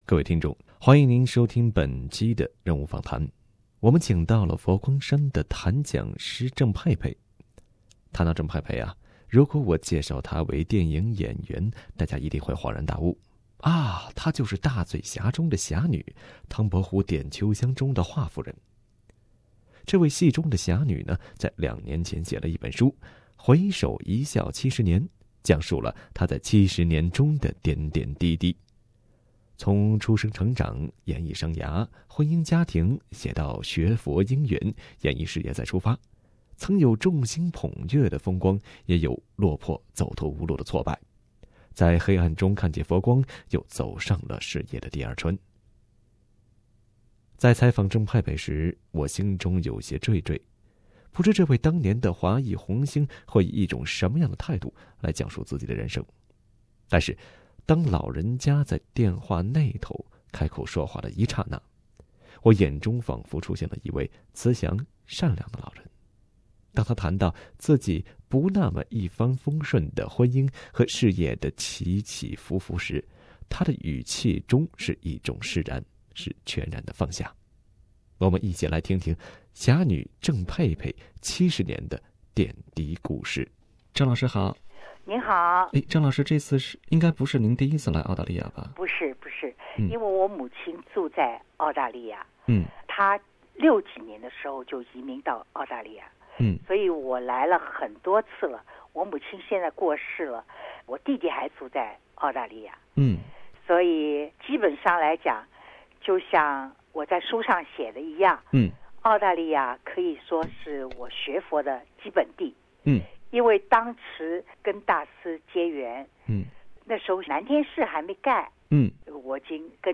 专访：郑佩佩和她的“佛系生涯”